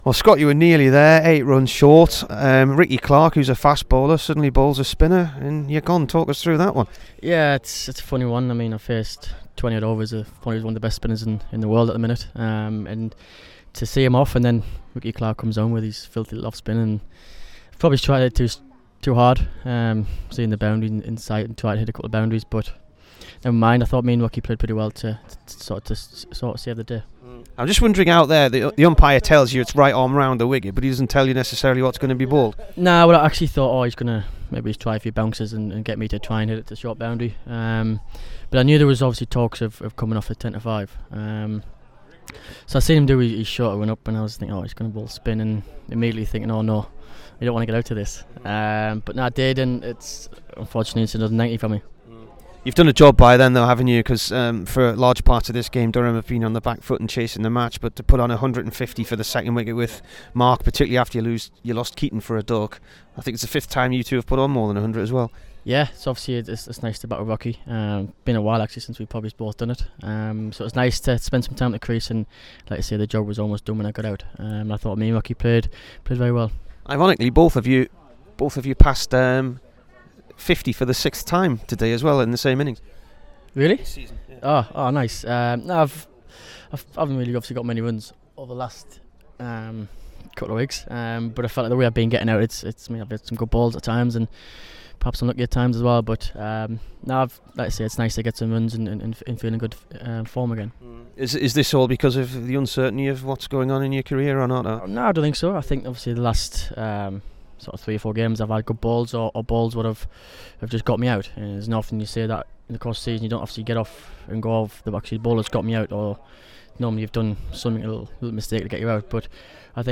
SCOTT BORTHWICK INT
HERE'S THE DURHAM ALL-ROUNDER AFTER HIS 92 RUNS IN THE DRAW WITH WARWICKSHIRE.